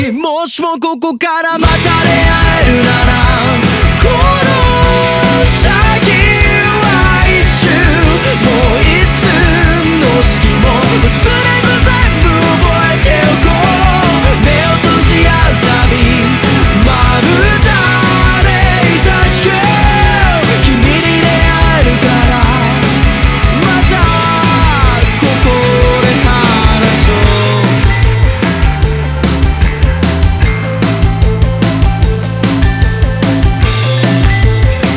2025-07-13 Жанр: Рок Длительность